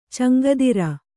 ♪ caŋgadiru